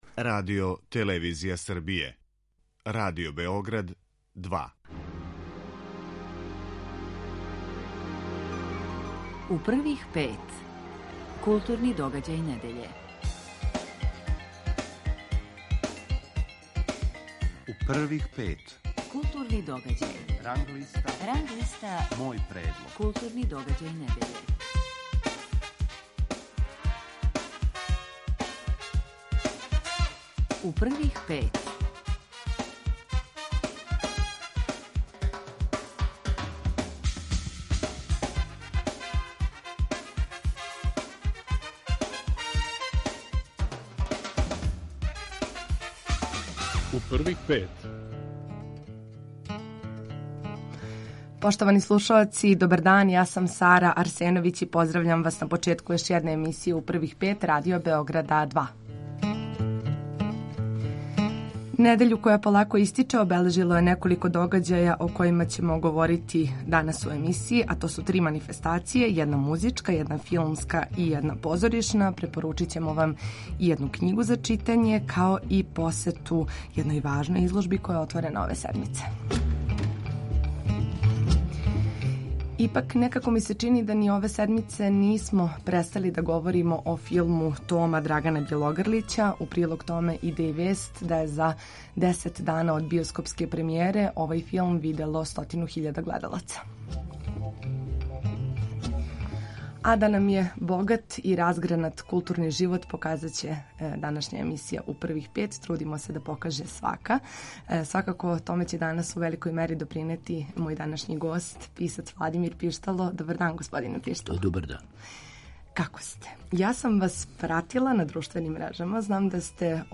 Гост емисије је Владимир Пиштало.